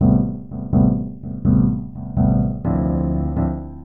SYNTH021_VOCAL_125_A_SC3(R).wav
1 channel